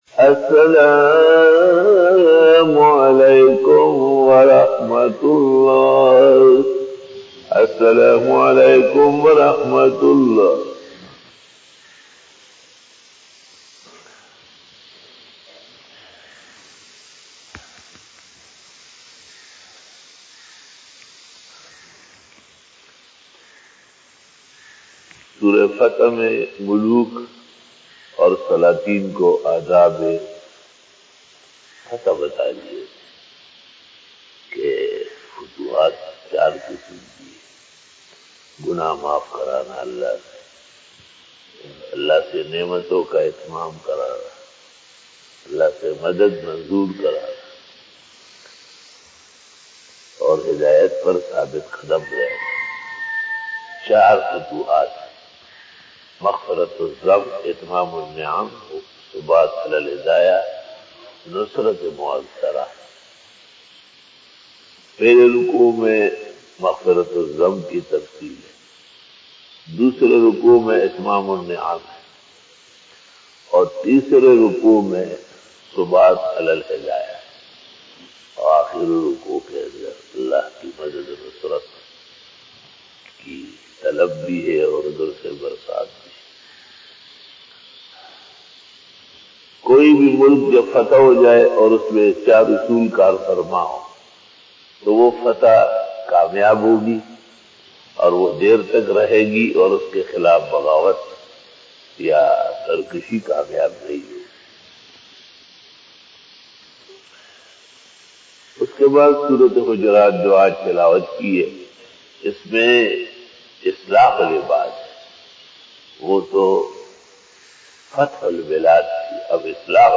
Fajar bayan 10 November 2020 ( 23 Rabi ul Awwal 1442HJ) Tuesday